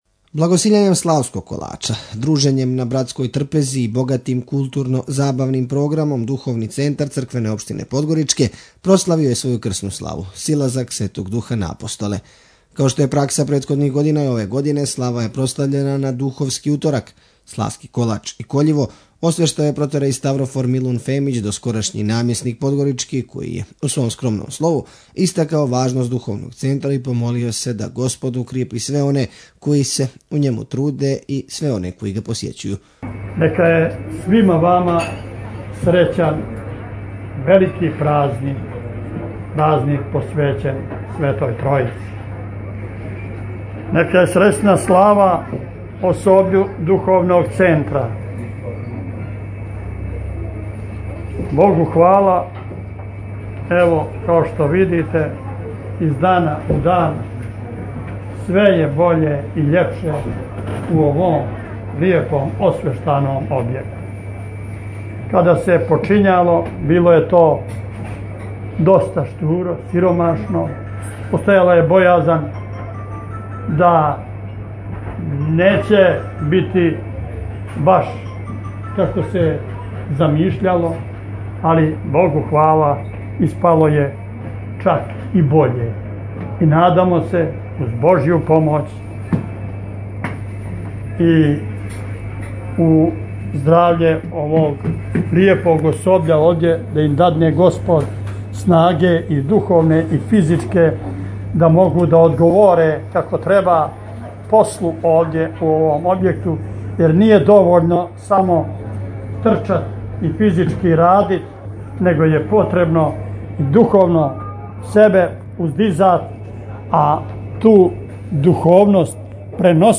Благосиљањем славског колача , дружењем на братској трпези и богатим културно – забавним програмом Духовни Центар Црквене Општине Подгоричке прославио је своју крсну славу – Силазак Светог Духа на Апостоле. Као што је пракса претходних година, и ове године слава је прослављена на Духовски Уторак.
Извјештаји